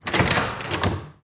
SFX金属门15推门横条开放(doormetal15push音效下载
SFX音效